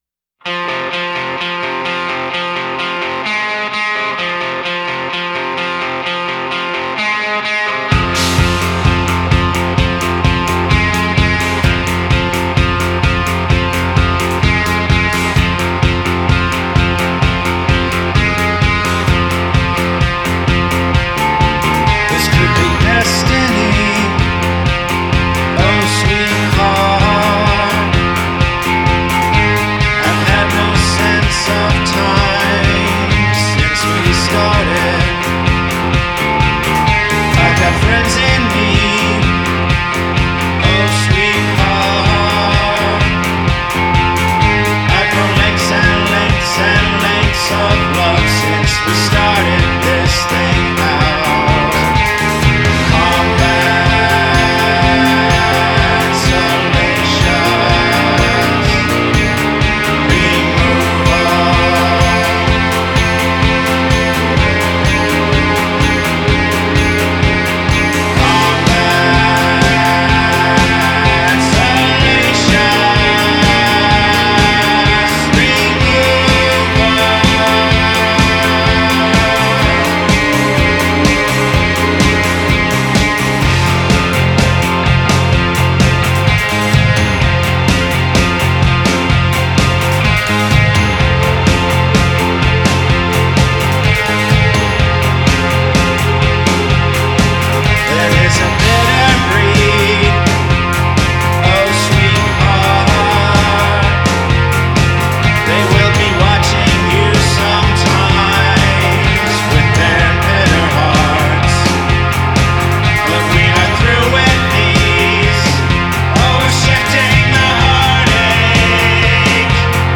• Жанр: Альтернатива